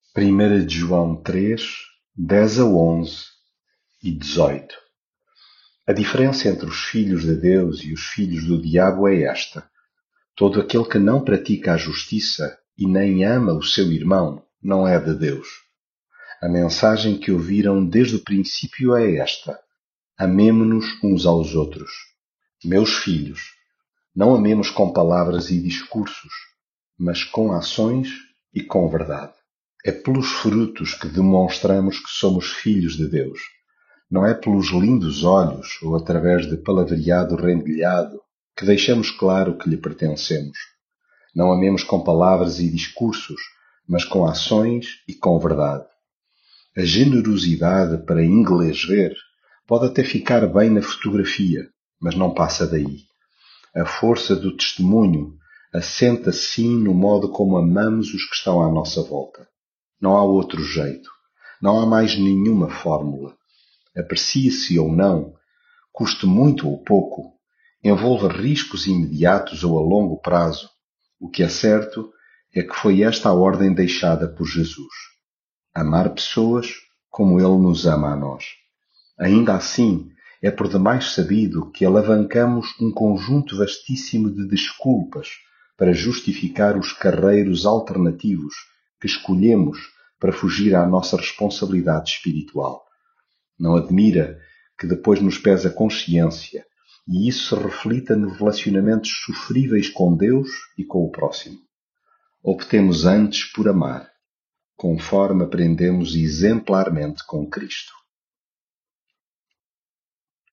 leitura bíblica